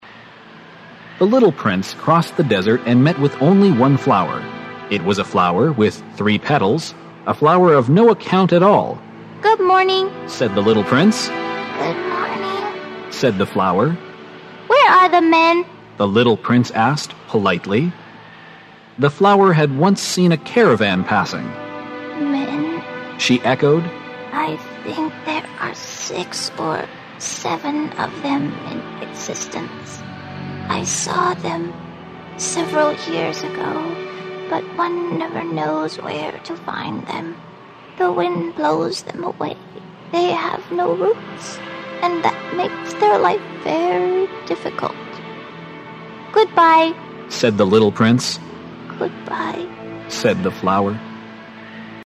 本栏目包含中英文本和音频MP3文件，让我们随着英文朗读与双语文本一起出发，重温这部经典之作，寻找灵魂深处的温暖。